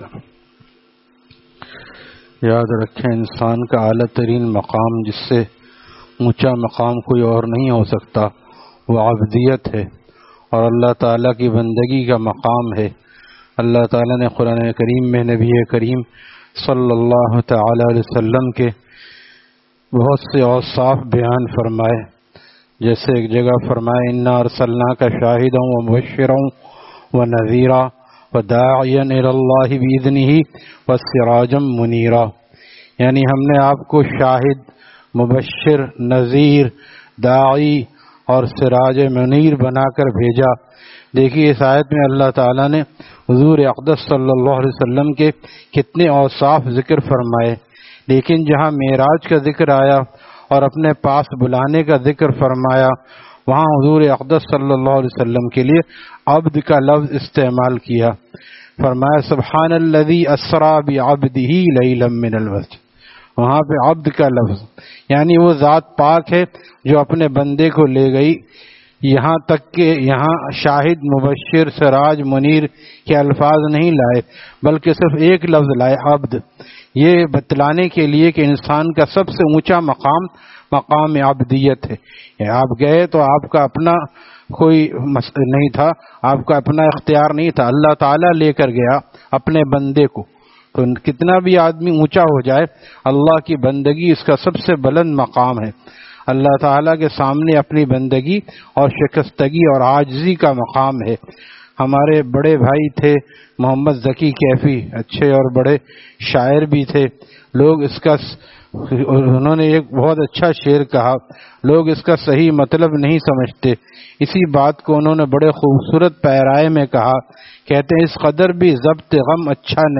Taleem After Fajar at Jamia Masjid Gulzar e Mohammadi, Khanqah Gulzar e Akhter, Sec 4D, Surjani Town